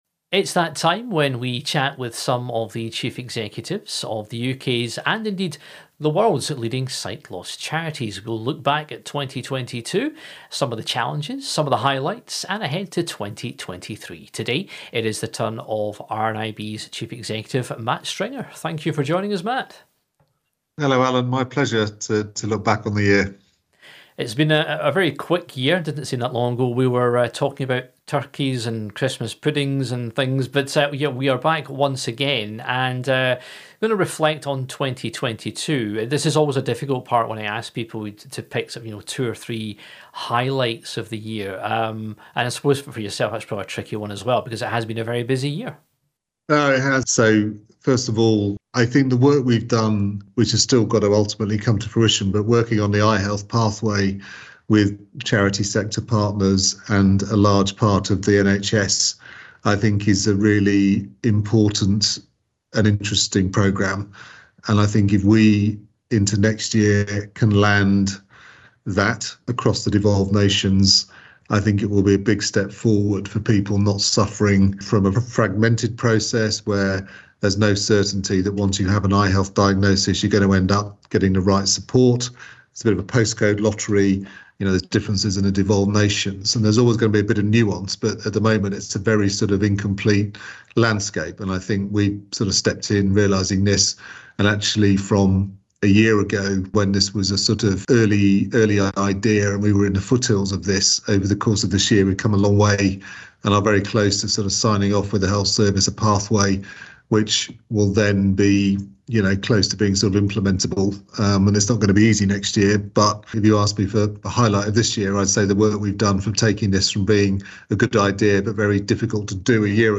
sit down with some of the CEOs of some of the UK's, and world's, leading sight loss charities.